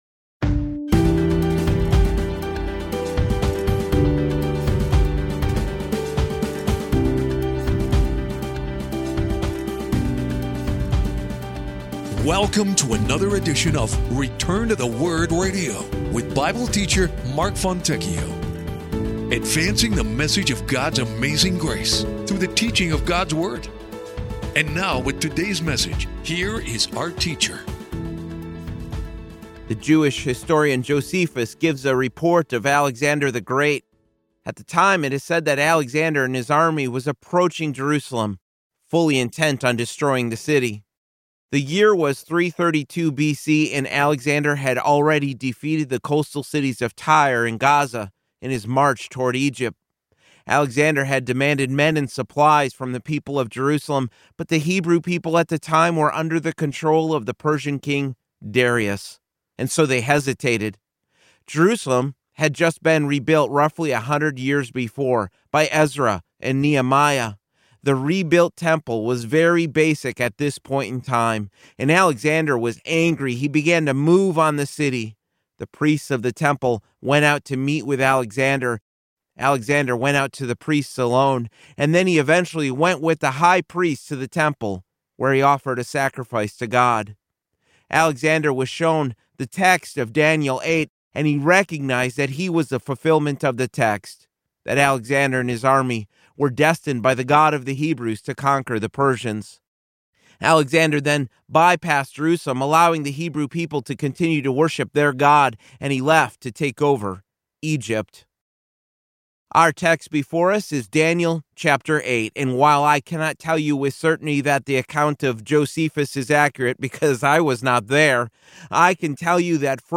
Teaching God's Word and advancing the message of His amazing grace one verse at a time.